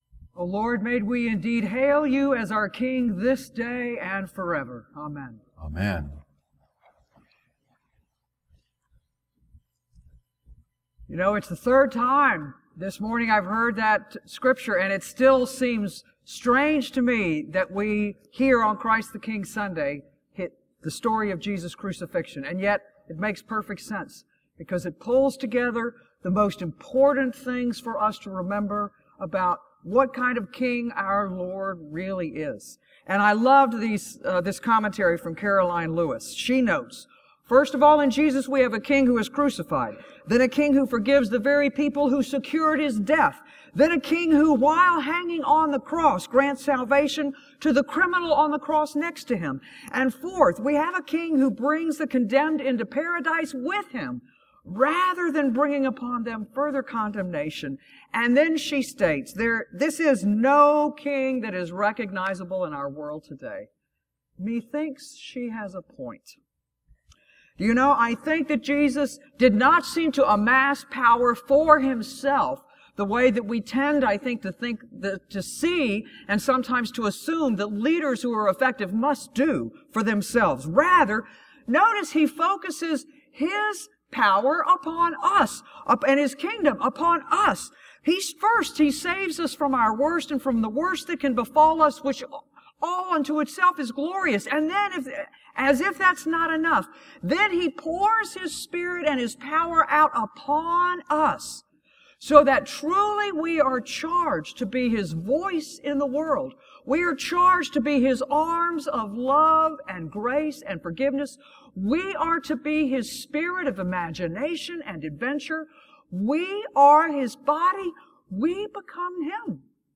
Sermons at Christ Episcopal Church, Rockville, MD
Sunday Sermon